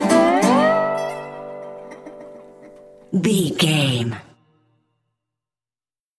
Ionian/Major
electric guitar
acoustic guitar
drums
ukulele